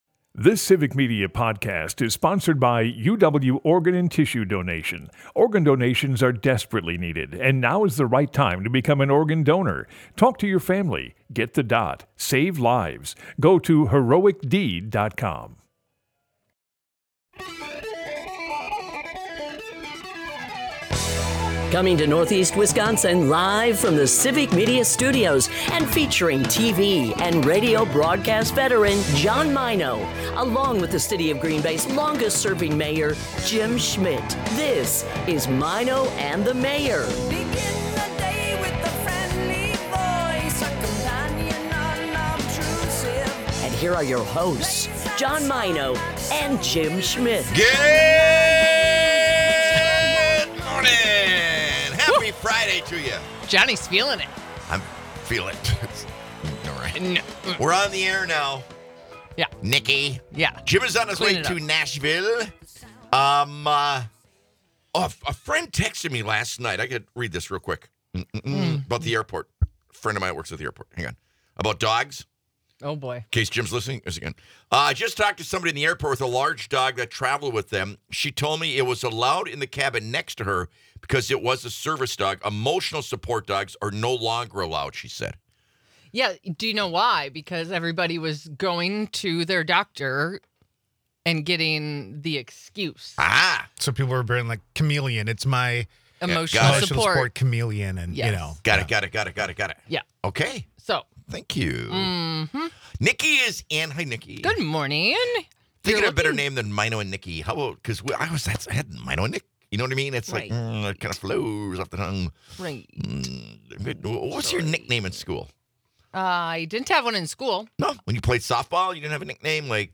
The conversation rolls all over the place.